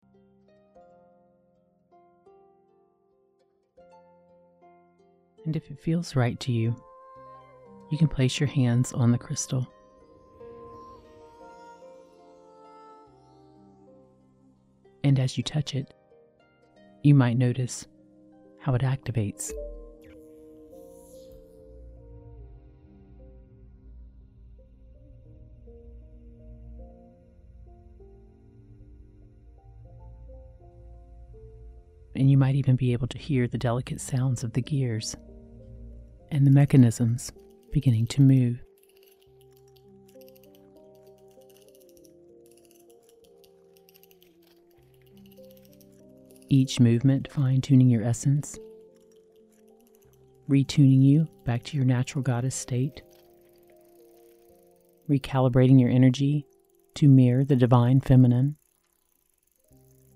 Dive deep into the essence of your divine femininity with this 8-hour extended hypnosis meditation session. Utilizing theta waves, this meditation is expertly designed to awaken and empower your inner goddess with advanced hypnotic techniques.
Theta Waves Enhancement: Theta waves are used throughout to enhance your receptivity to hypnotic suggestions.
Powerful Affirmations: Following the initial guided visualization, the session includes a continuous loop of affirmations.
3D Audio Effects: In-depth 3D audio effects are strategically used to create a realistic and immersive auditory experience.